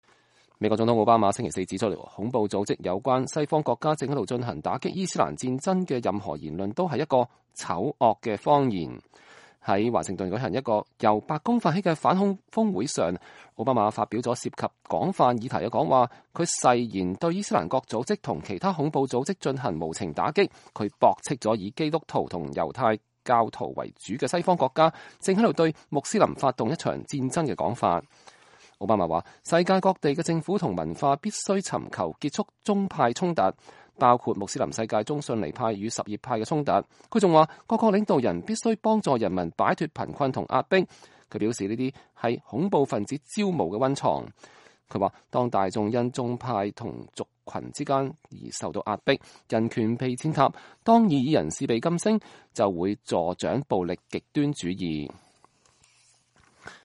奧巴馬在反恐峰會發表講話